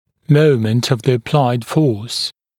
[‘məumənt əv ðə ə’plaɪd fɔːs][‘моумэнт ов зэ э’плайд фо:с]момент прилагаемой силы